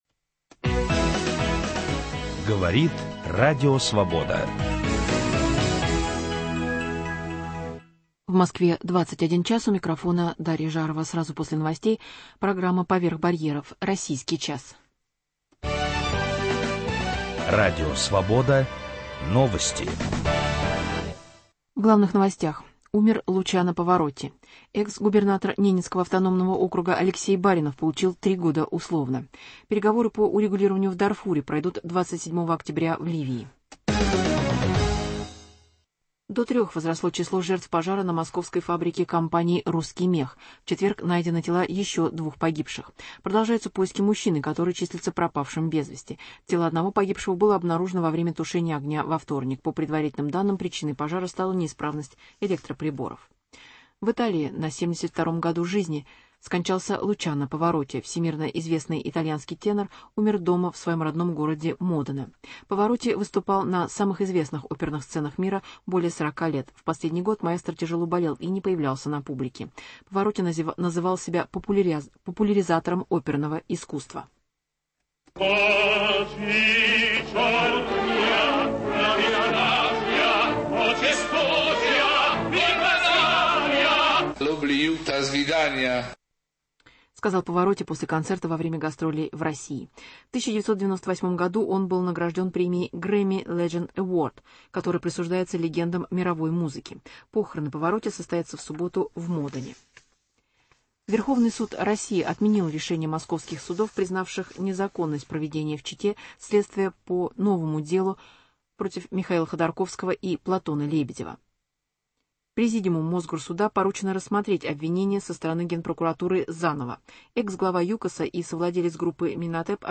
Интервью с артистом МХТ Борисом Плотниковым.